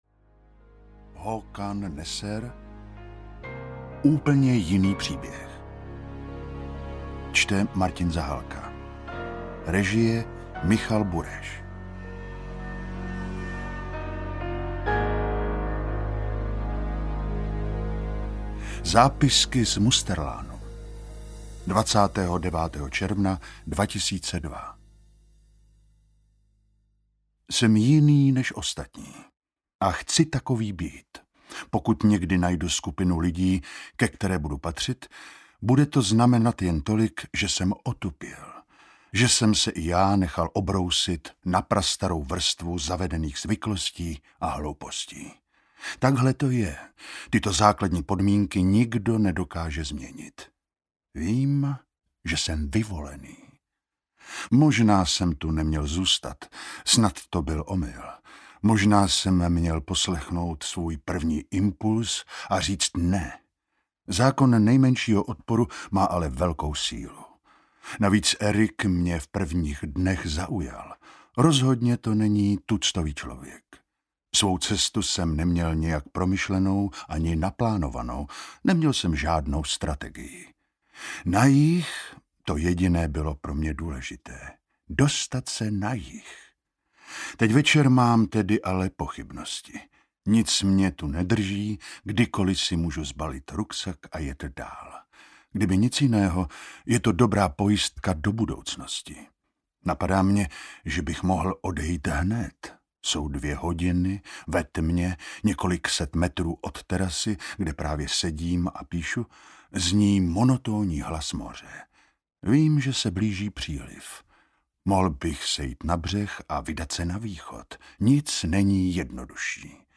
Úplně jiný příběh audiokniha
Ukázka z knihy
• InterpretMartin Zahálka